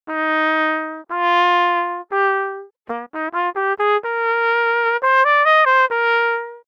6. CSIS Instrument Model performance, using measured amplitude and frequency curves + sampled attack
(Same setup as with the previous example, augmented with the attack sample splicing technique)